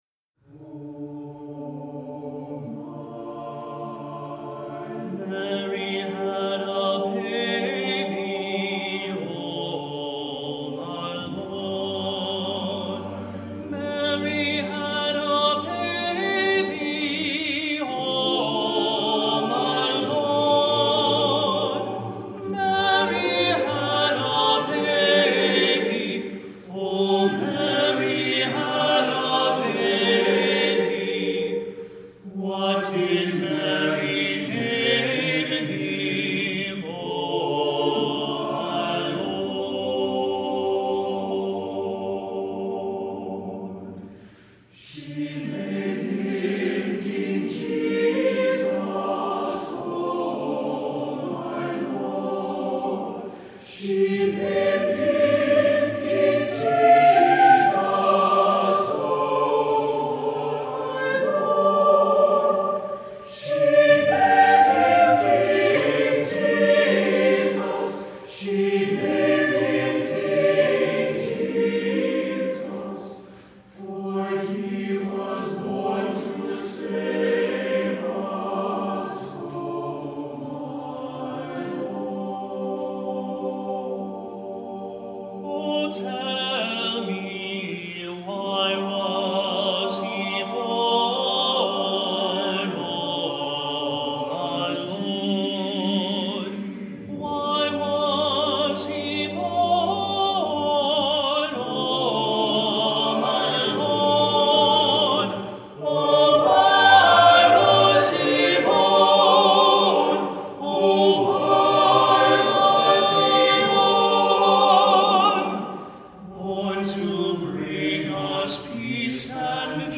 Composer: Spiritual
Voicing: SATB a cappella